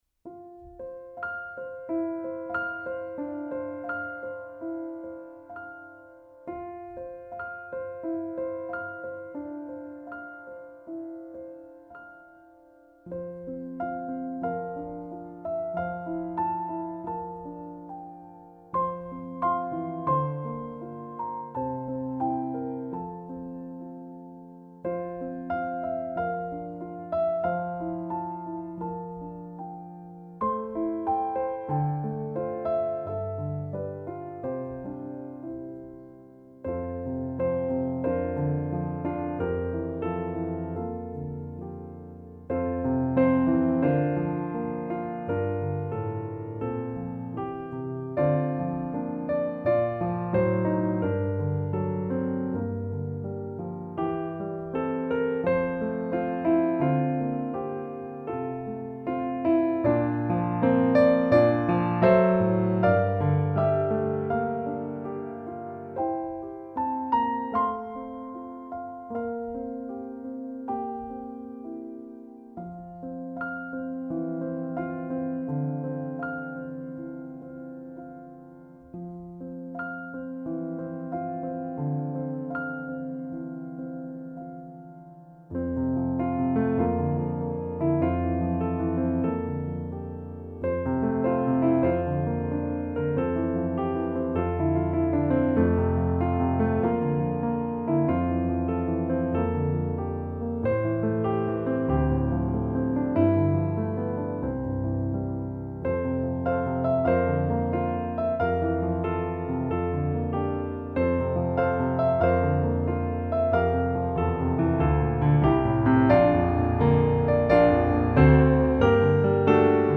Instrumentation: Solo Piano
Sacred, Gentle, Soft